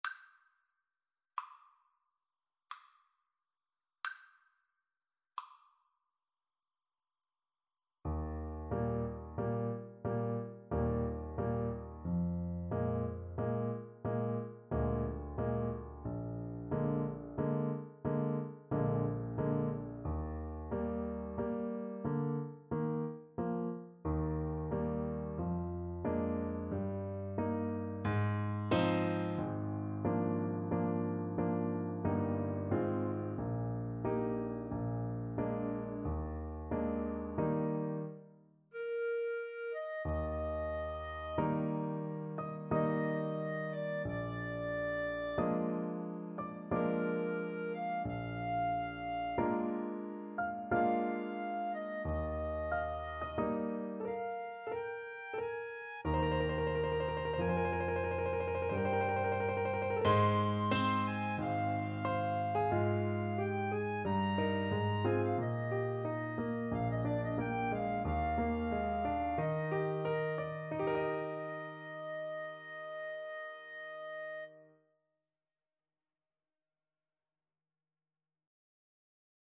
Adagio =45
Classical (View more Classical Mixed Trio Music)